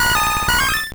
Cri d'Empiflor dans Pokémon Or et Argent.